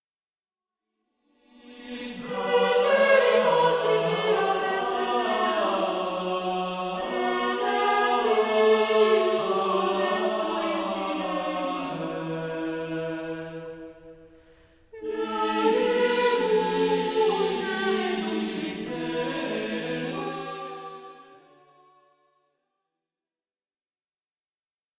Motet sur Ite missa est